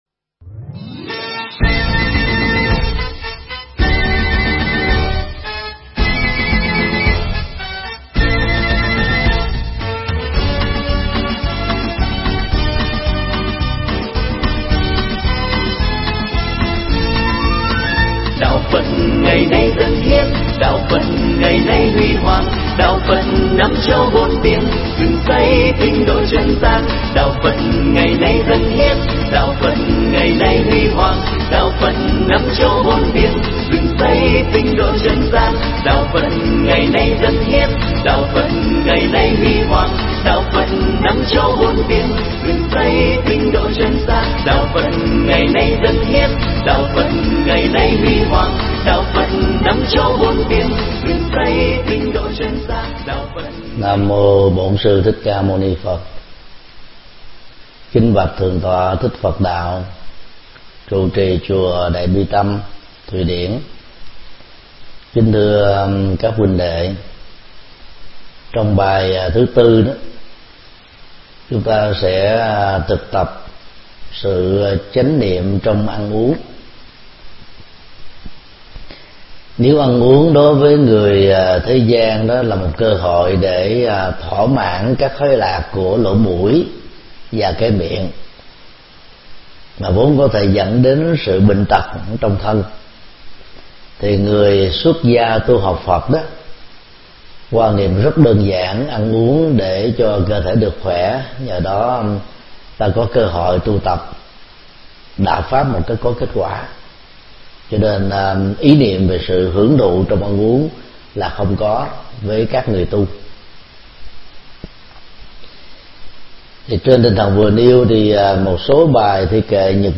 Mp3 Pháp Thoại Tỳ ni nhật dụng 04
giảng tại chùa Đại Bi Tâm, Thụy Điển